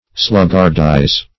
Sluggardize \Slug"gard*ize\, v. t.